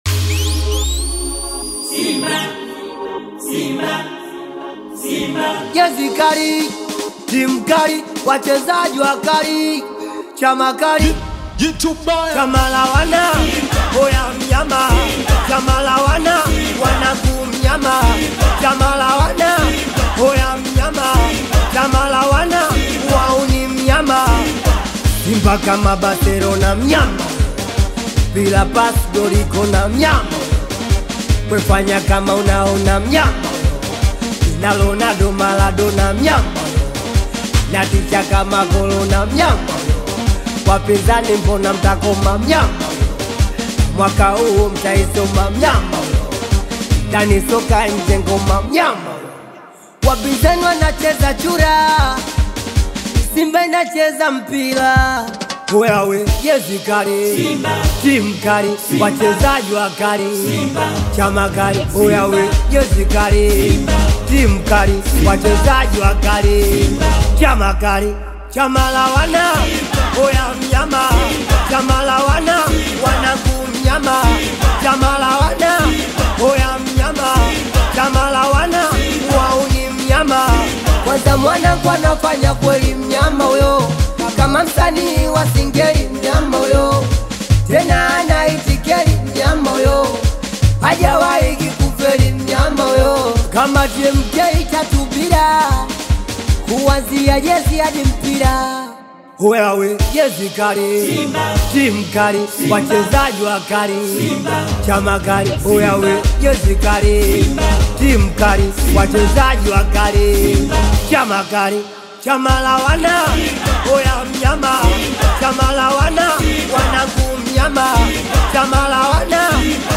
Known for blending vibrant rhythms with heartfelt lyrics